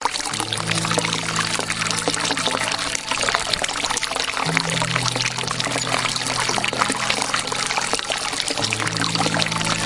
有轻微音乐背景的流动温泉水
描述：运行温泉水与轻微的音乐背景
Tag: 流水声 飞溅 运行